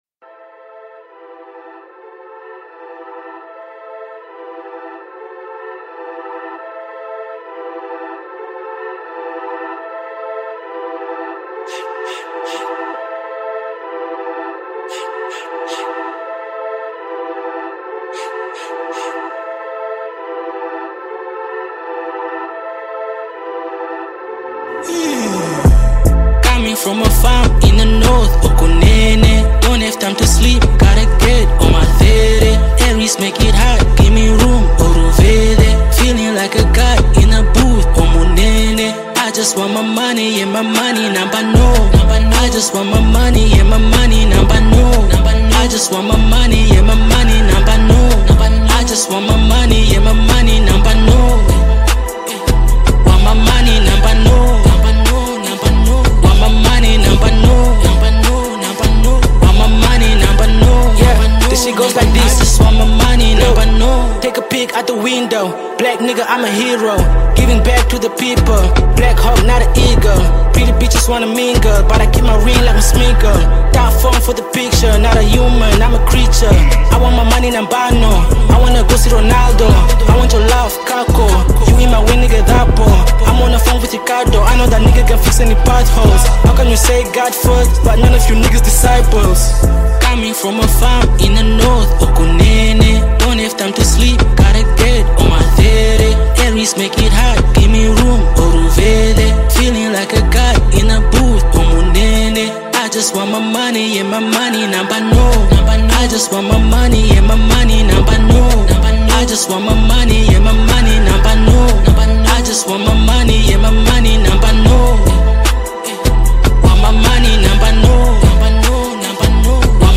Hip-hopMusic